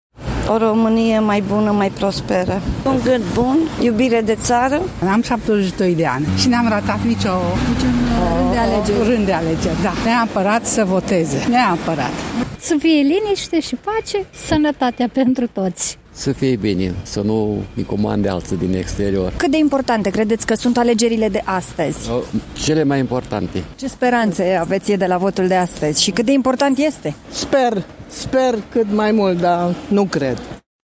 Sursa: Radio România Brașov FM